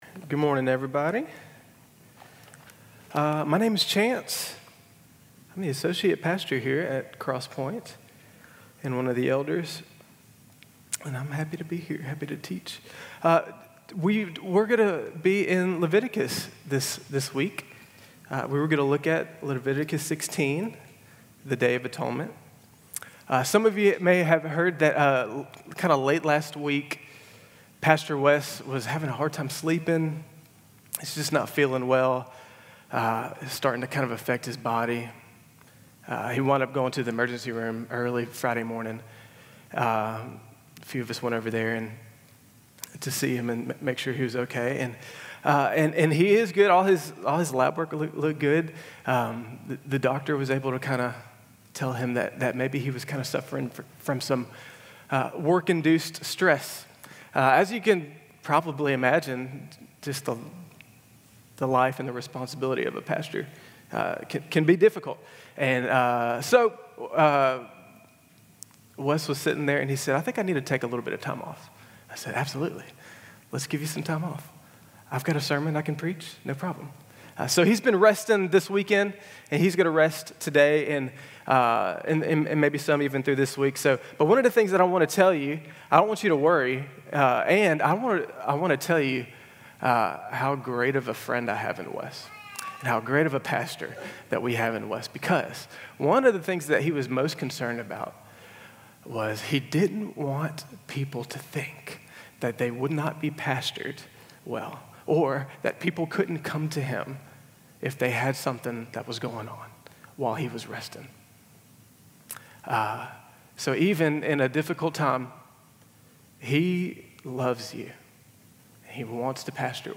Bulletin and Sermon Notes 11-16-25